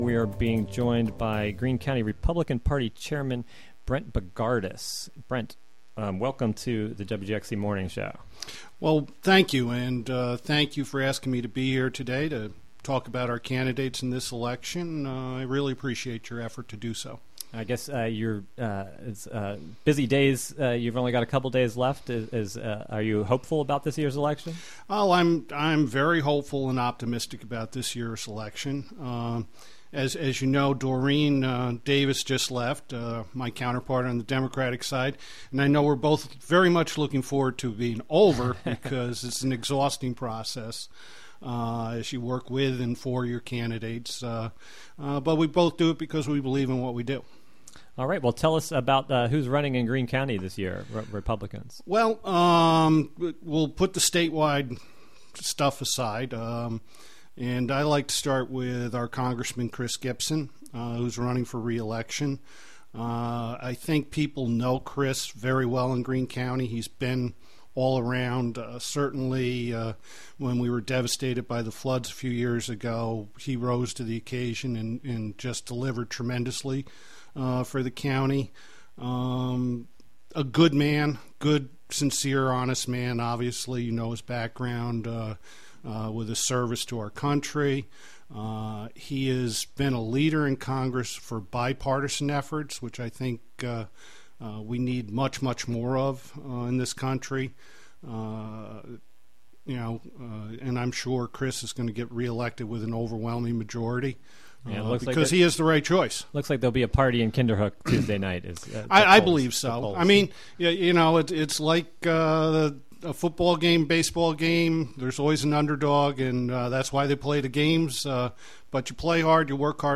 10am Local interviews, local news, regional event previ...